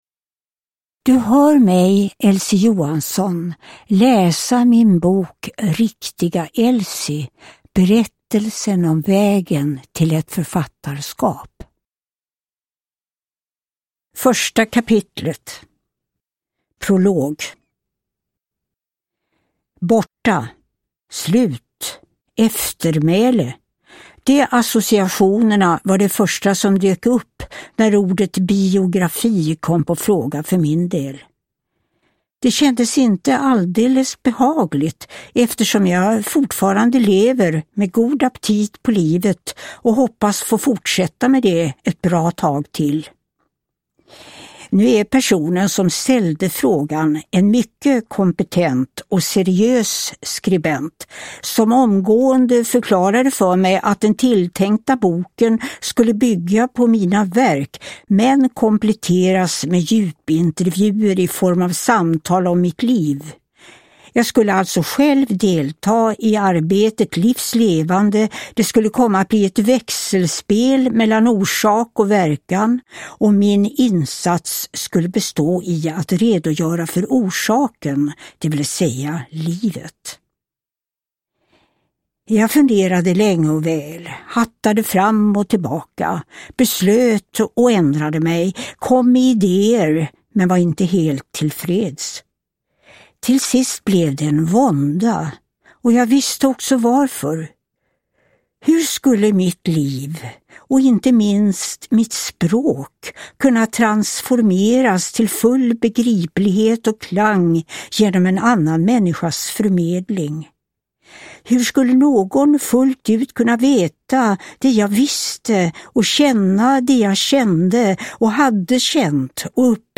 Uppläsare: Elsie Johansson
Ljudbok